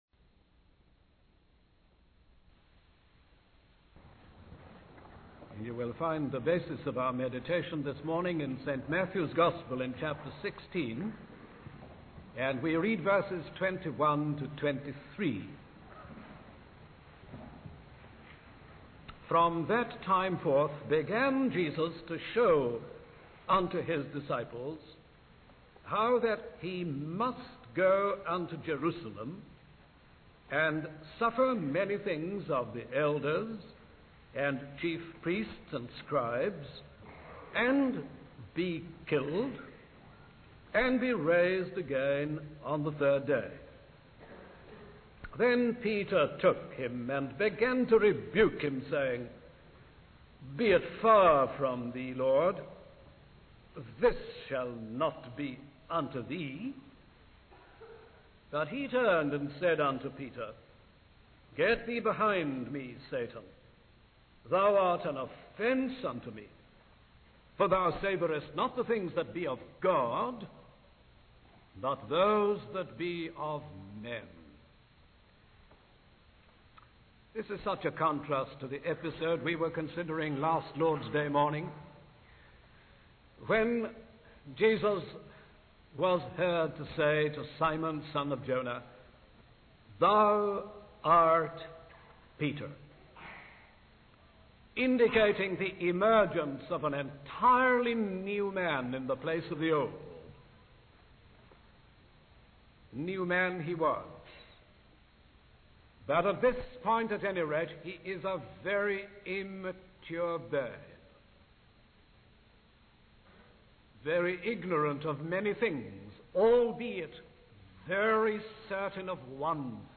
In this sermon, the speaker addresses the danger of transitioning from a spiritual state to a carnal one. He emphasizes the importance of distinguishing between the certainty given by God and our own human ignorance. The speaker then discusses how Jesus dealt with a disciple who was influenced by carnality.